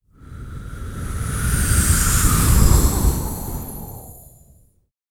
Index of /90_sSampleCDs/ILIO - Vocal Planet VOL-3 - Jazz & FX/Partition H/4 BREATH FX